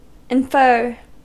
Ääntäminen
Ääntäminen US : IPA : [ɪn.fɜː]